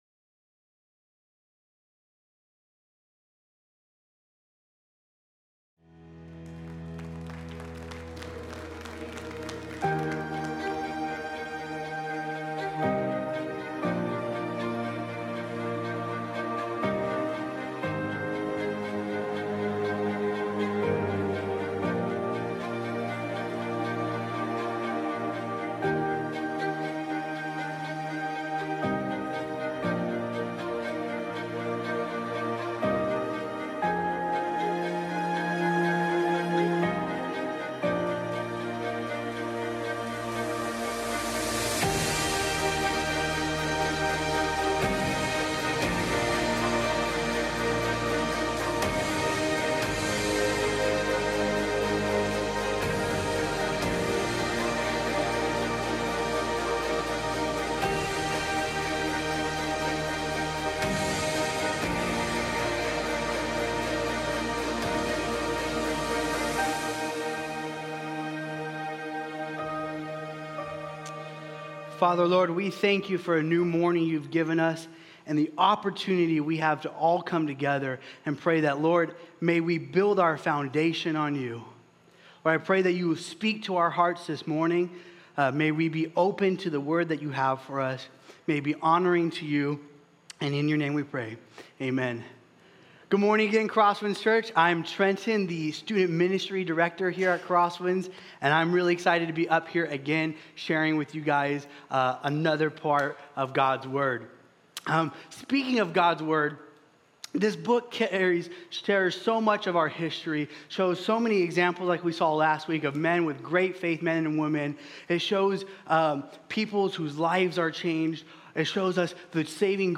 Sermons | CrossWinds Church